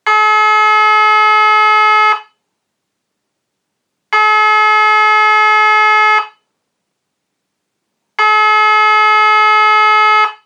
Sirena elettronica per interno ed esterno.
Di medie dimensioni questa sirena ha 3 tonalità diverse di suono.
PULSATO.mp3